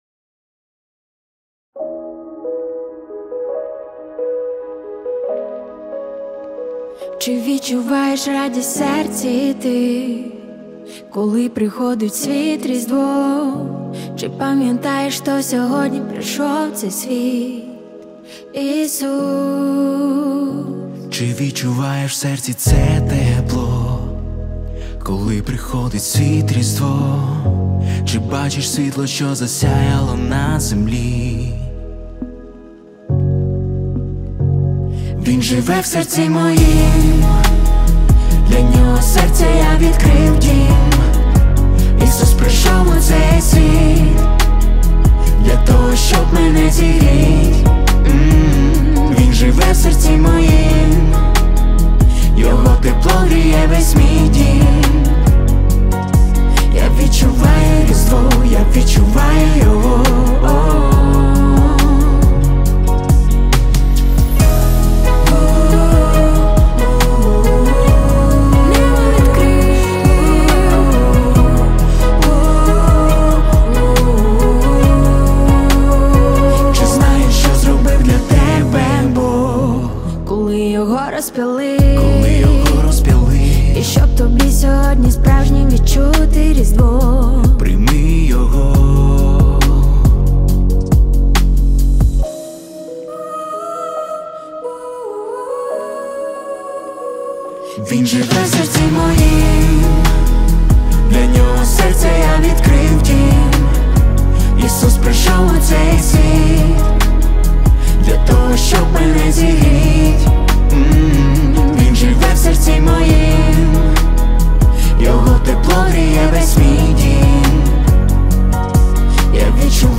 1289 просмотров 1660 прослушиваний 323 скачивания BPM: 70